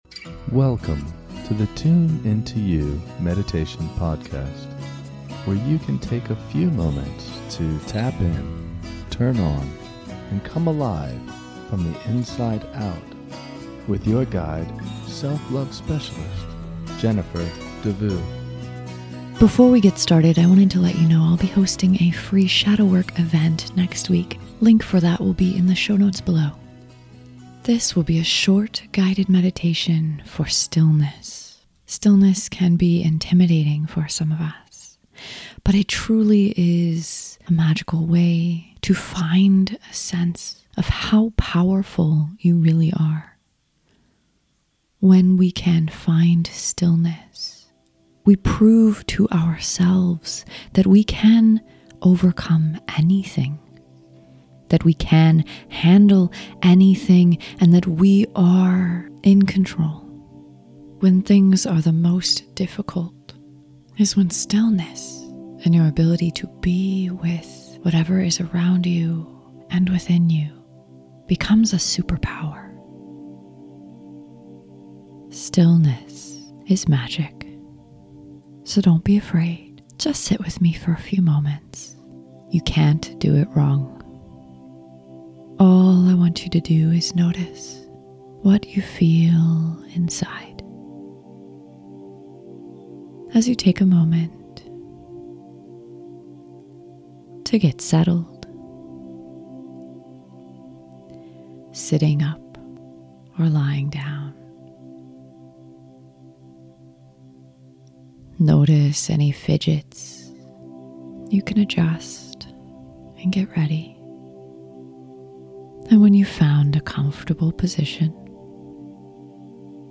This is a short guided meditation to connect with stillness.